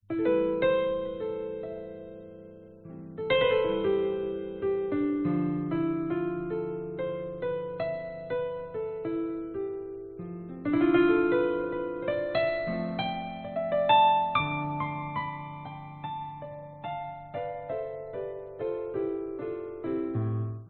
钢琴即兴演奏
Tag: 钢琴